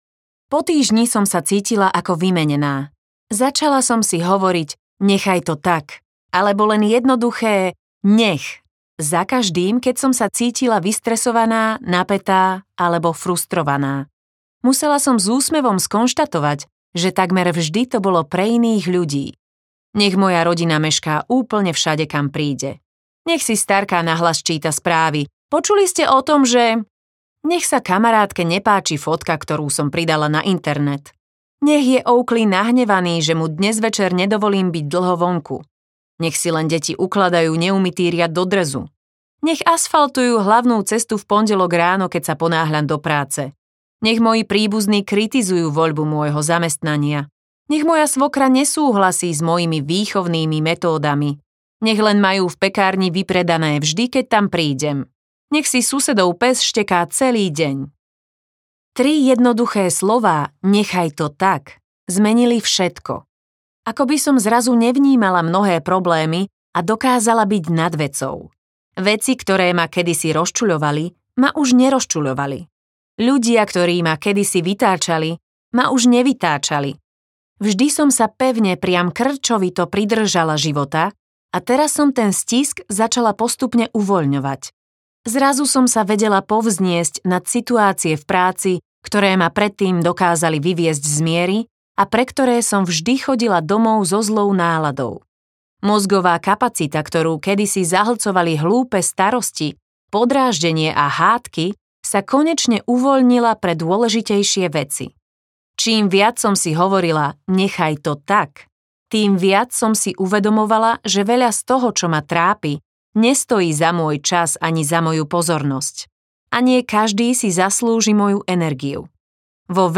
Nechajte to tak audiokniha
Ukázka z knihy